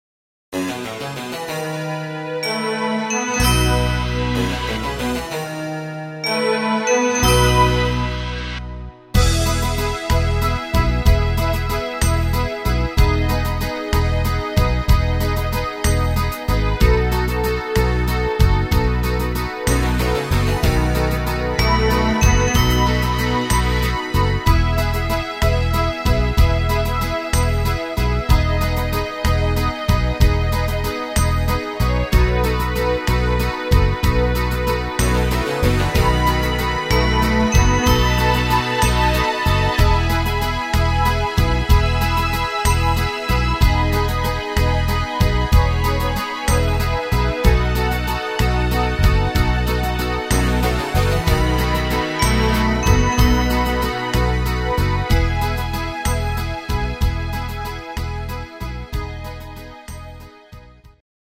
instr. Panflöte